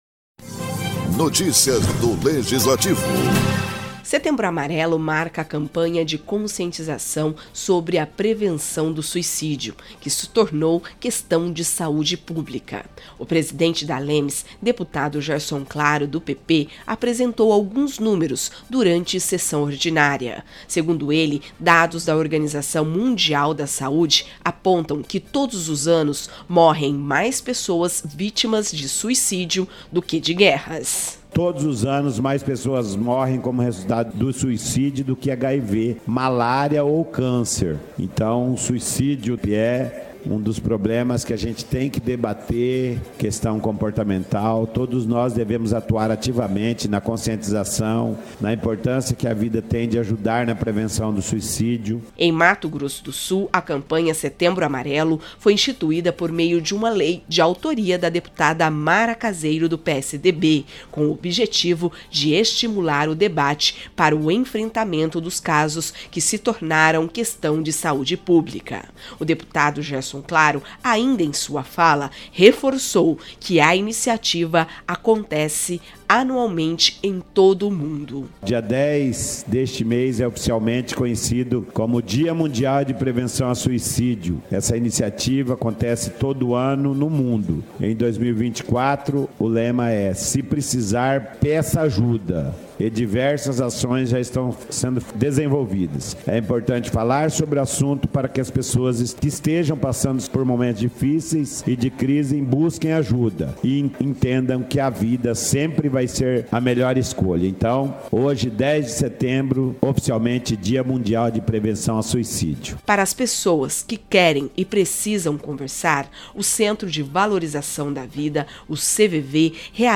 Setembro Amarelo marca a campanha de conscientização sobre a prevenção do suicídio, que se tornou questão de saúde pública. O presidente da ALEMS, deputado Gerson Claro, apresentou alguns números, durante sessão ordinária.